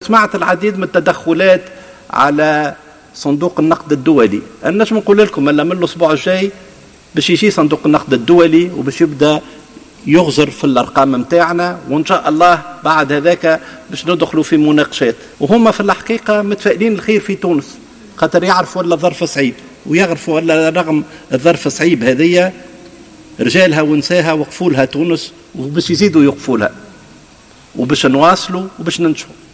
أكد وزير الاقتصاد والمالية ودعم الاستثمار، محمد علي الكعلي، خلال الجلسة العامّة المخصصة لمناقشة مشروع قانون المالية لسنة 2021، مساء الأحد أن وفدا عن صندوق النقد الدولي سيكون في تونس الأسبوع القادم للنظر في الأرقام الحالية لتونس للبدء في نقاشات جدية بعد ذلك.